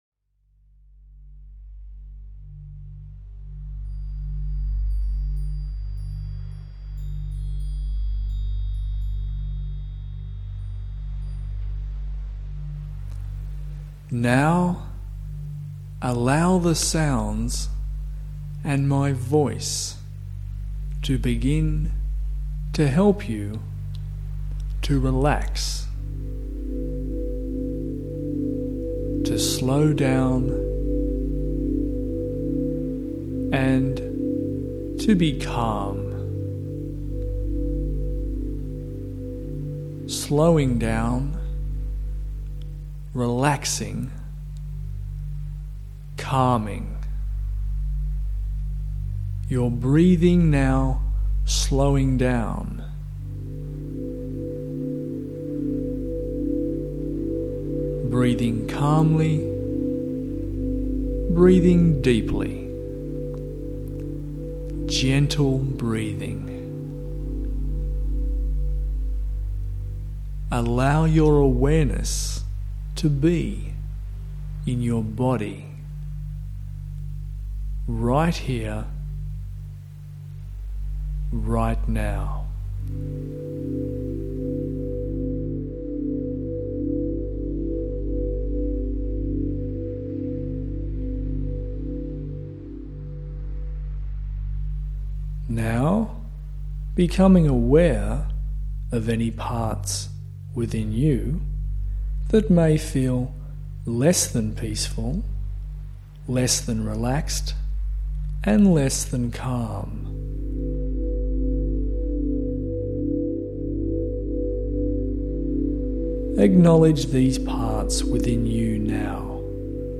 This is an easy meditation that will only take 7 minutes and it will relax you completely, be it after a hectic day or before a meeting. It is best used through stereo headphones.
7_min_deep_relax.mp3